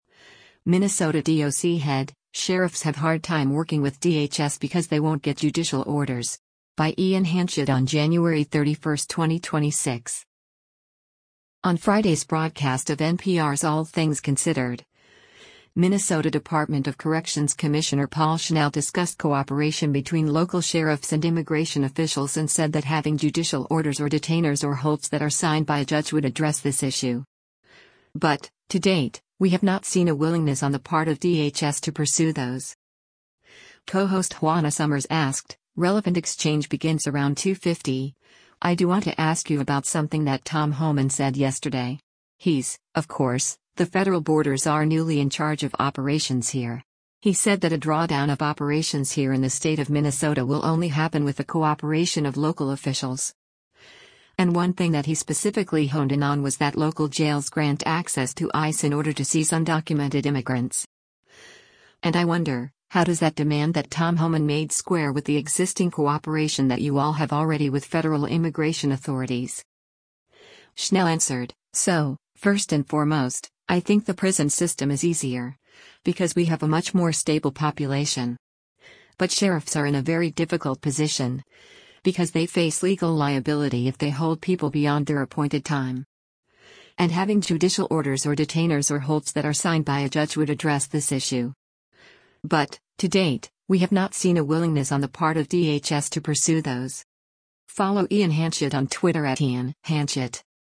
On Friday’s broadcast of NPR’s “All Things Considered,” Minnesota Department of Corrections Commissioner Paul Schnell discussed cooperation between local sheriffs and immigration officials and said that “having judicial orders or detainers or holds that are signed by a judge would address this issue. But, to date, we have not seen a willingness on the part of DHS to pursue those.”